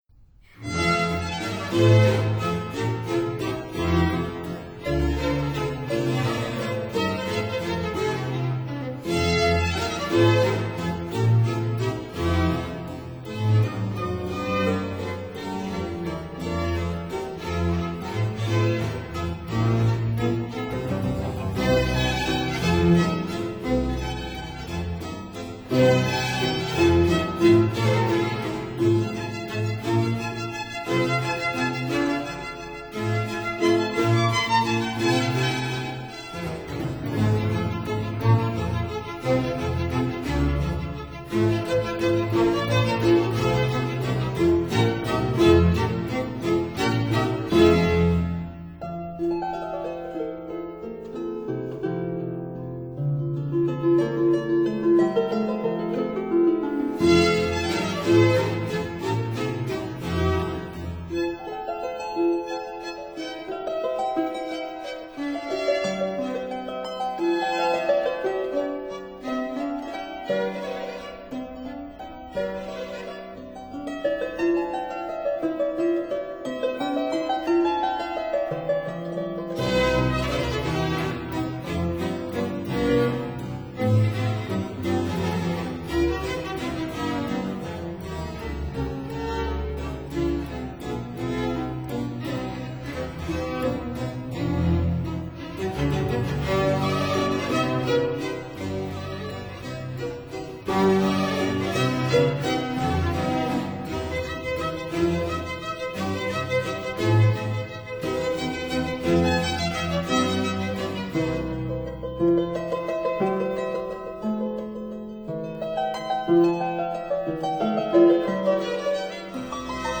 Sinfonias, Harp Concerto,
Violin Concerto, Oboe Concerto